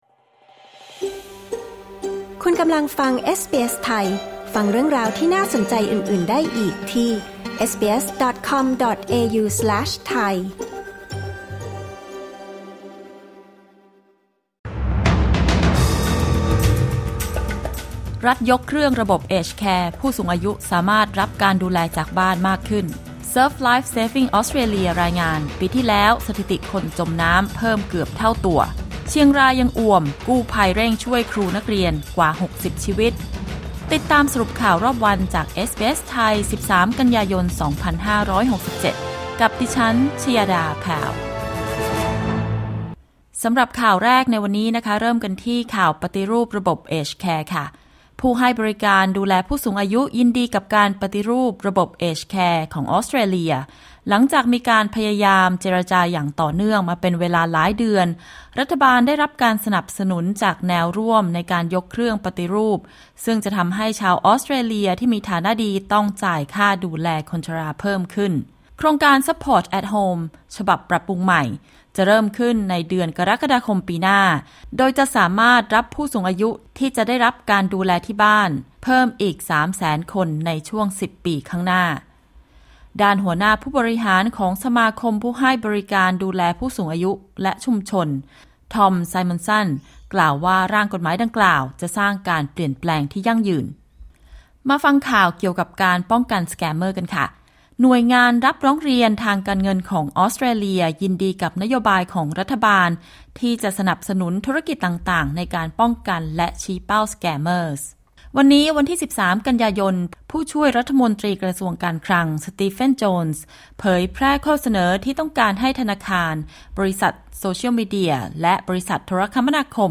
สรุปข่าวรอบวัน 13 กันยายน 2567